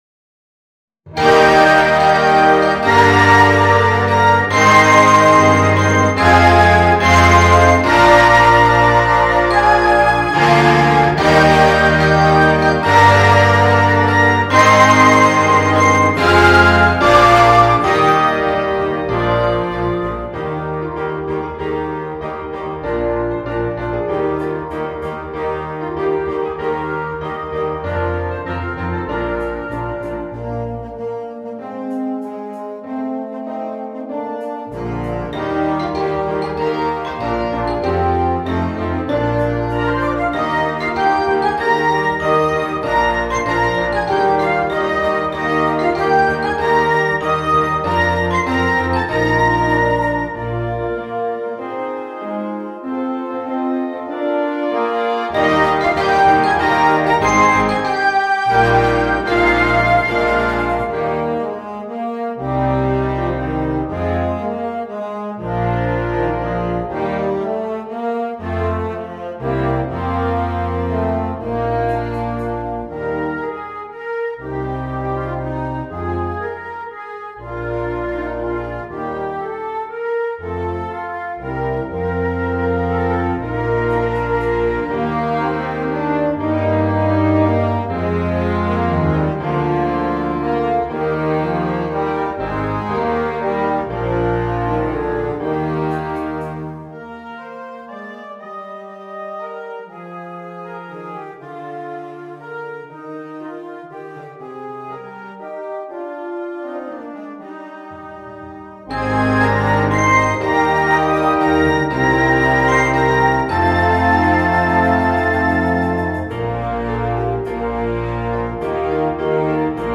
2. Concert Band
Full Band
without solo instrument
Christmas Music
Medley